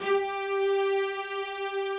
Updated string samples
strings_055.wav